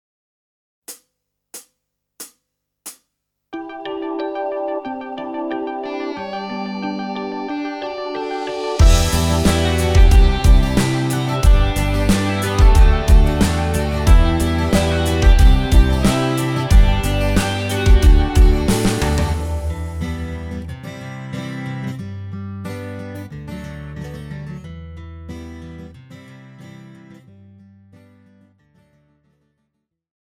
KARAOKE/FORMÁT:
Žánr: Pop
BPM: 91
Key: Em